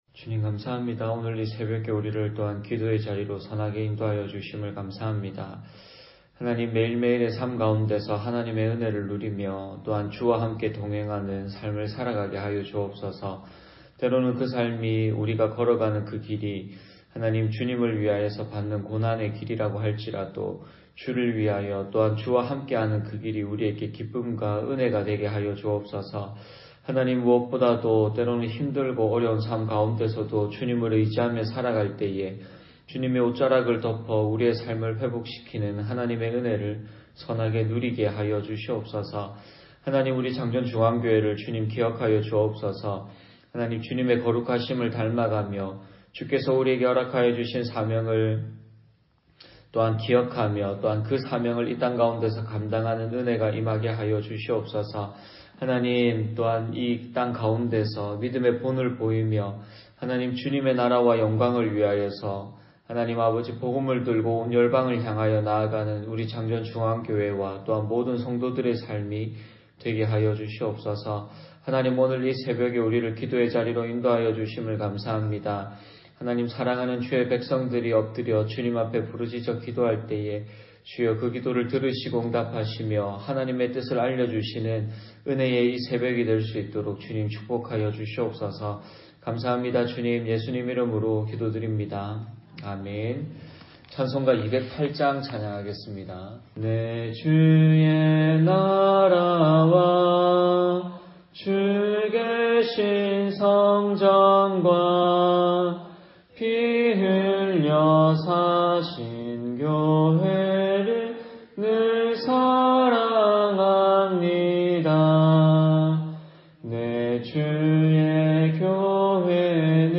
10월 26일(토) 새벽기도 말씀 입니다